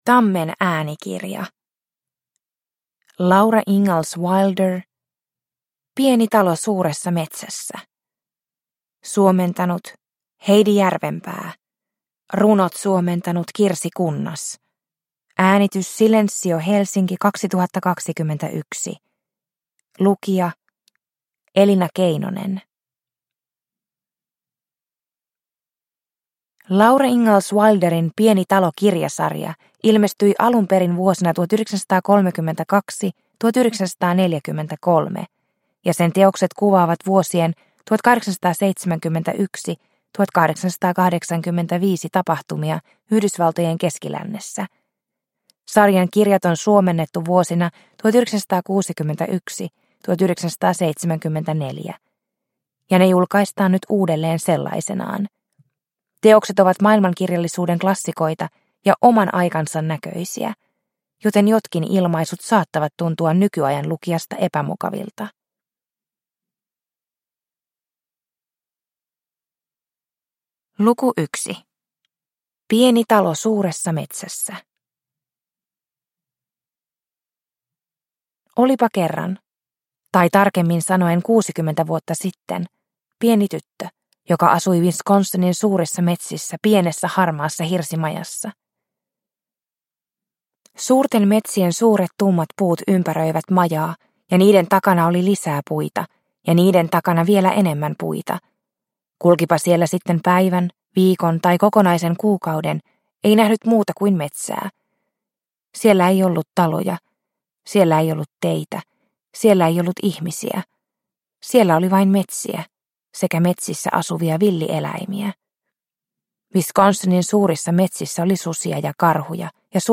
Pieni talo suuressa metsässä – Ljudbok – Laddas ner